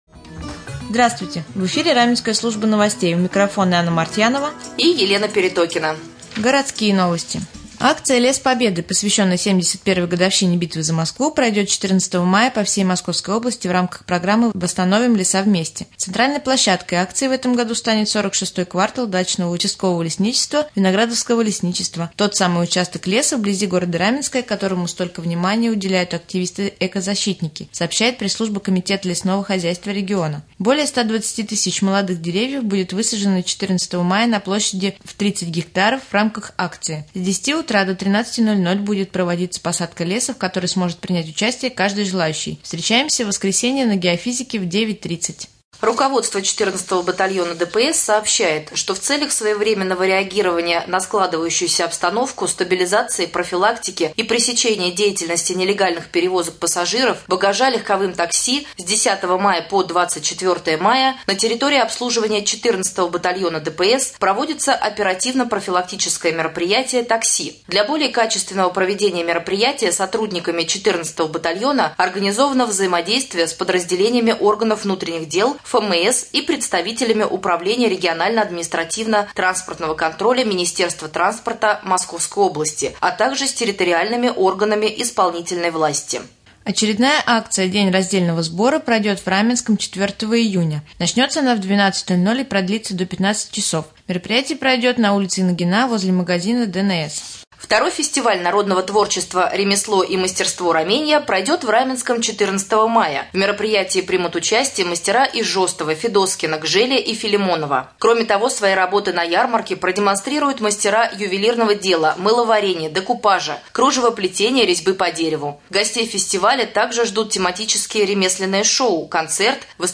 1. Новости 2. В прямом эфире глава района Владимир Демин